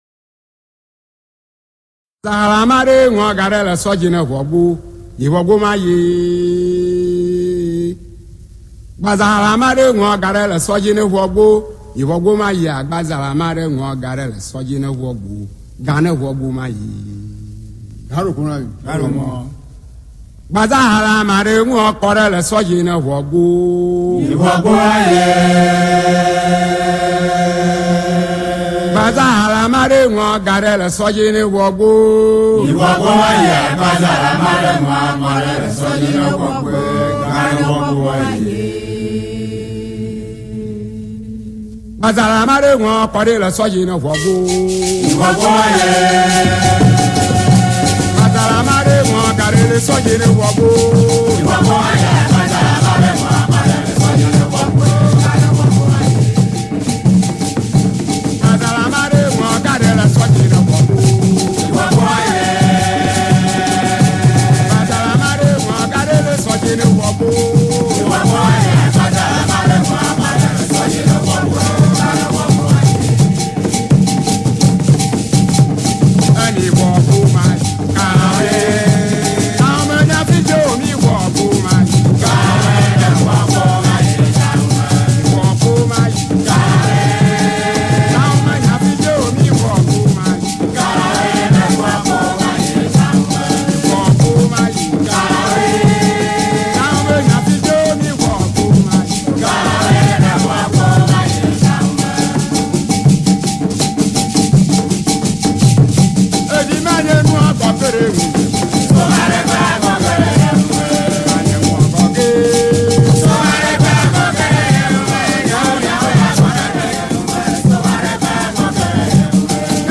00_Agbadza-2_Agbadza-2.mp3